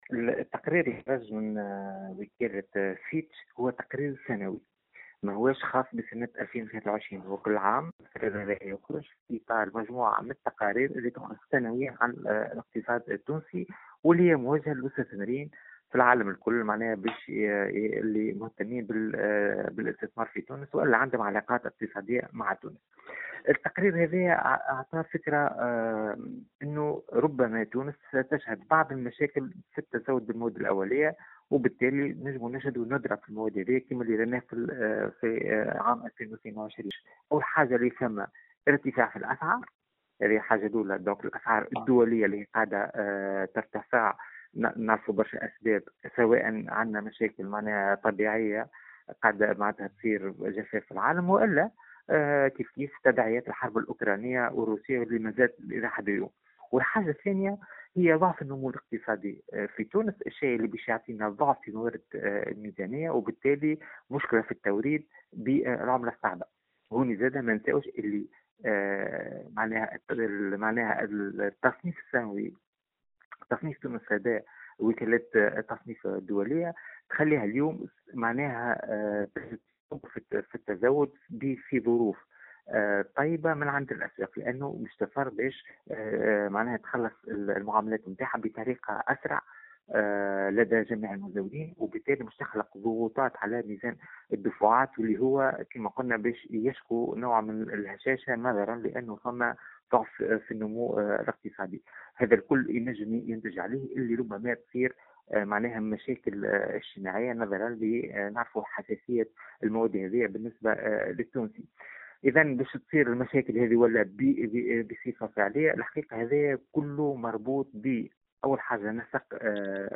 La crise des produits de base est liée aux accords signés avec les fournisseurs (Déclaration)